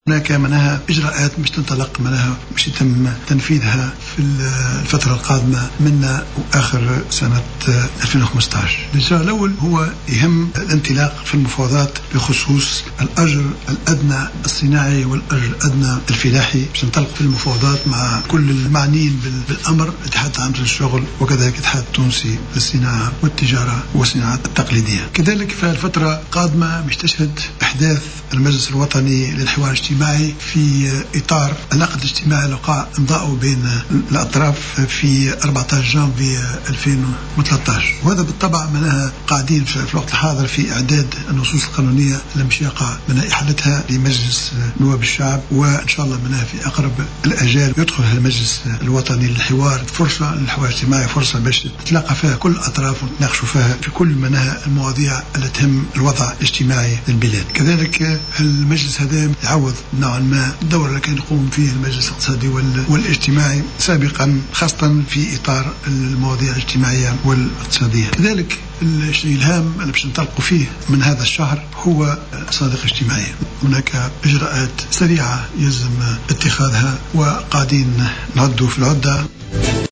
وأكد الصيد خلال ندوة صحفية عقدها بقصر المؤتمرات بالعاصمة إثر موكب احتفالي بمناسبة عيد الشغل العالمي أنه سيتم الانطلاق في التفاوض بخصوص الأجر الأدنى الفلاحي والأجر الأدنى الصناعي وأن النصوص القانونية المنظمة لإحداث المجلس الوطني للحوار الاجتماعي في طور الإعداد حاليا.